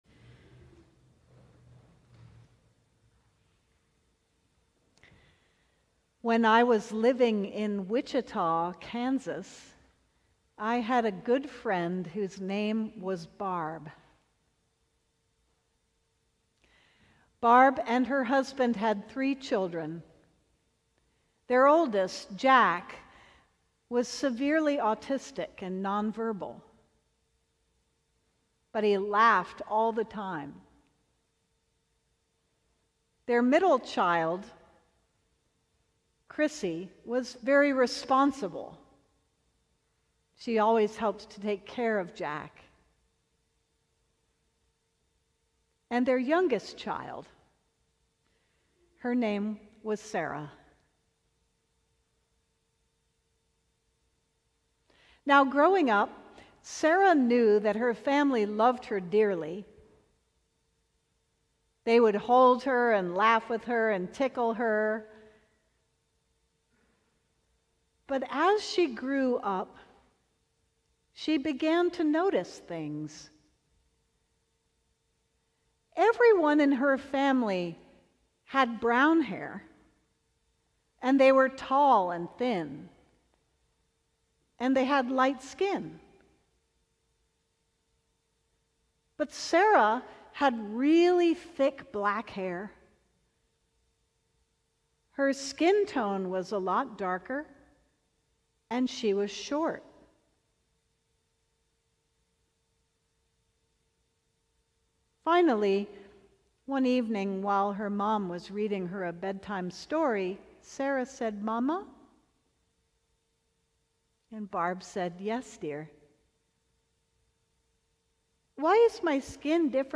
Sermon: Inheritance